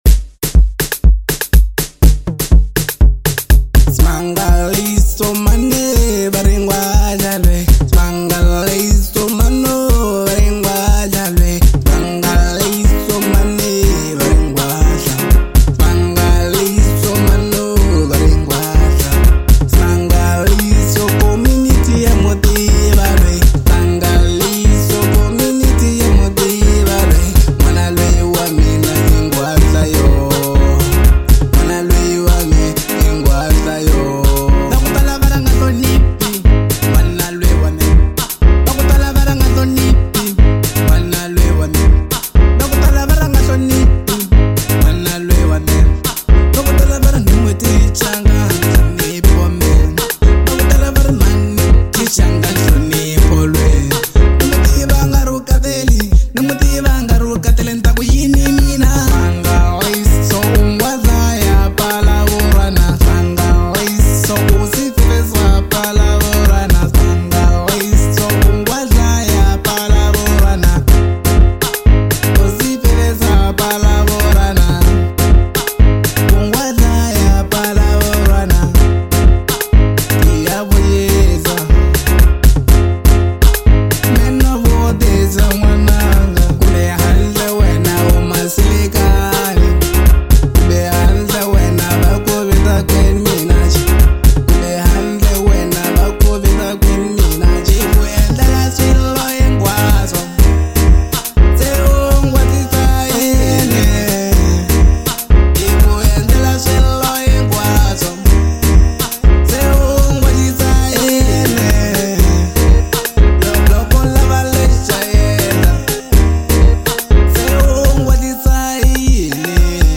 03:05 Genre : House Size